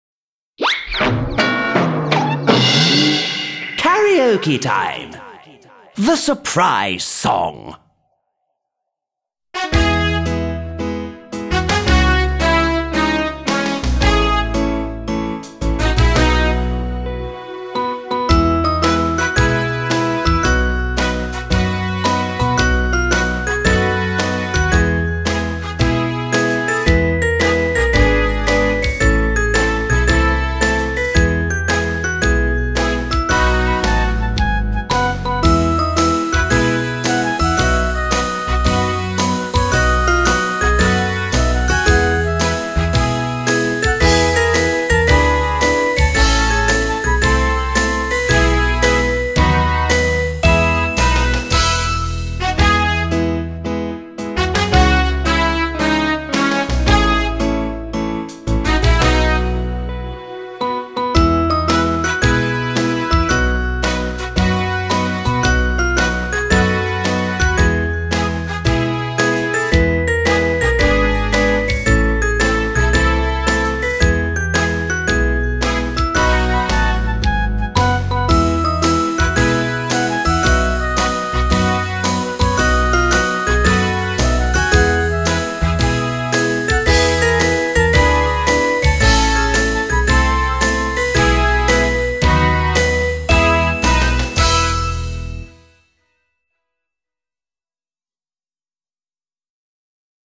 Фестиваль "День английского языка"
Песня "Сюрприз" [1] (поют дети 4 класса) Приложение 5; караоке